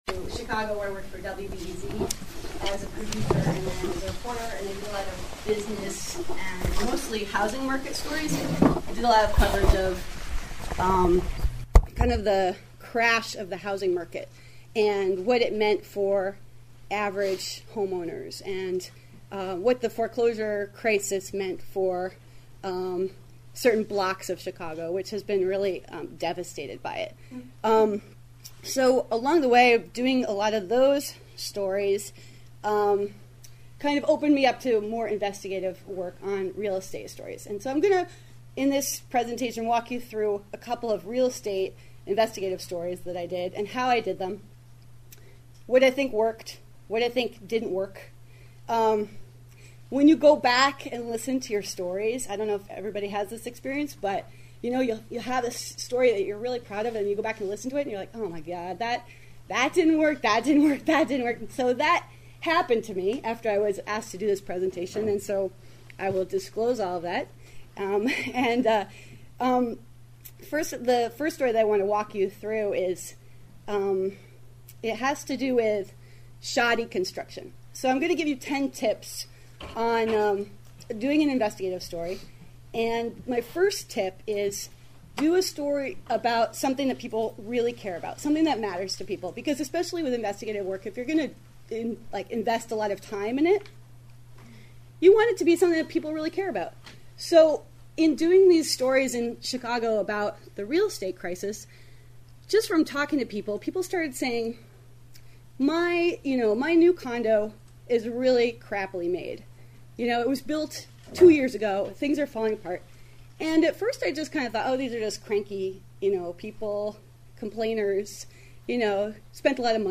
We weren’t able to document it all but here’s some coverage of the 2013 Alaska Press Club Conference.